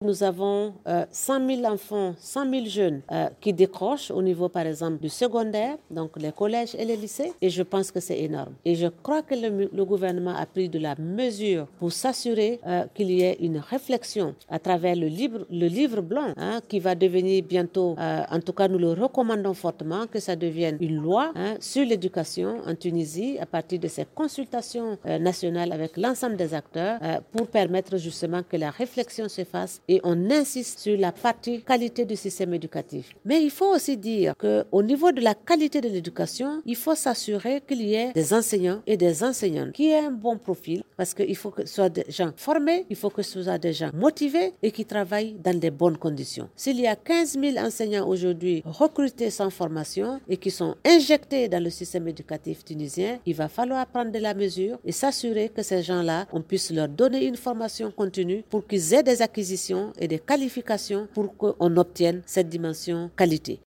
أوضحت المقررة الخاصة بالحق في التعليم بالأمم المتحدة كومبو بولي باري في تصريح لمراسل الجوهرة "اف ام" اثر ندوة صحفية عقدتها اليوم بالعاصمة لتقديم النتائج الأولية لمتابعة تقييم التقدم المحرز في الحق في التعليم بتونس أن 5 آلاف منقطع عن التعليم في تونس من فئة الأطفال والشباب.